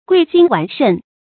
劌心鉥腎 注音： ㄍㄨㄟˋ ㄒㄧㄣ ㄕㄨˋ ㄕㄣˋ 讀音讀法： 意思解釋： 同「劌鉥心腑」。